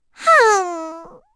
Cecilia-Vox_Sigh_b.wav